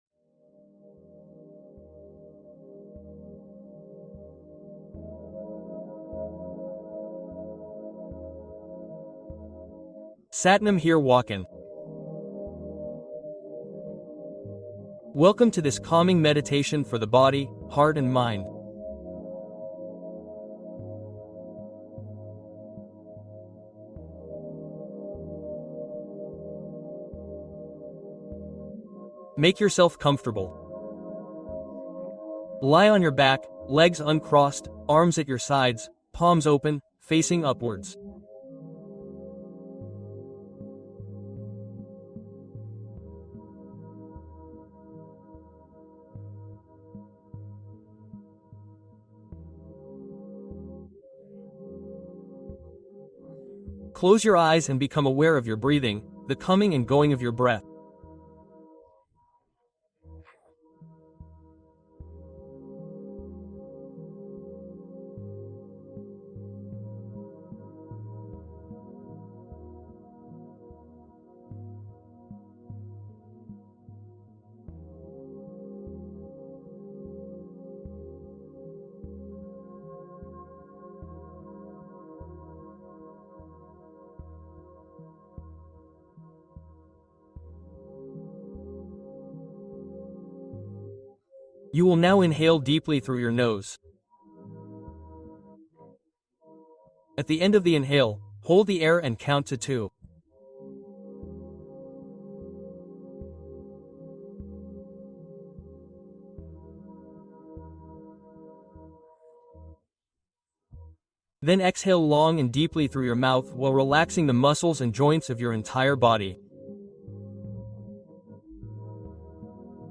Paix Intérieure : Méditation profonde pour stabiliser l'esprit